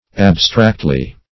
Search Result for " abstractly" : Wordnet 3.0 ADVERB (1) 1. in abstract terms ; The Collaborative International Dictionary of English v.0.48: Abstractly \Ab"stract`ly\ (#; 277), adv.